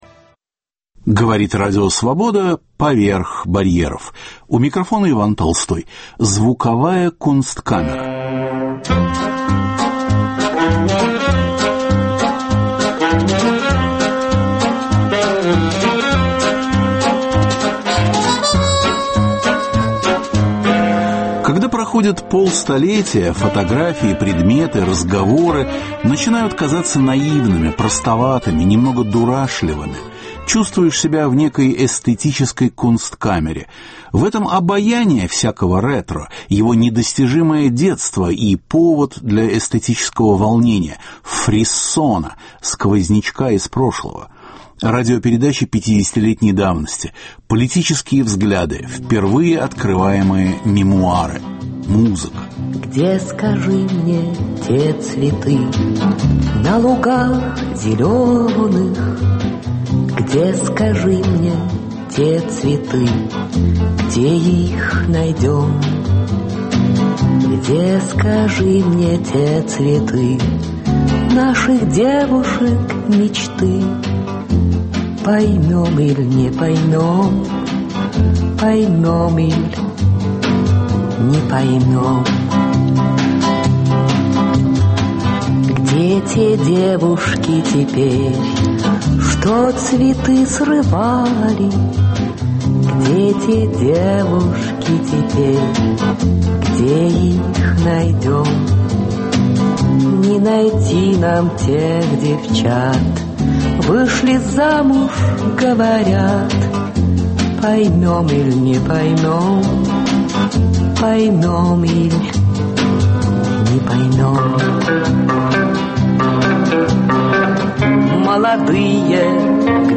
Радиопередачи пятидесятилетней давности.
Все записи - 1964 года.